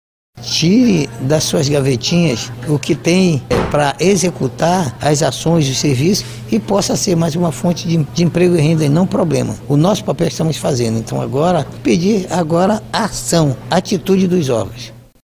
Após cinco anos da criação da legislação ainda há muito a ser realizado, de acordo com o deputado estadual, Sinésio Campos, presidente da Comissão de Geodiversidade da Aleam.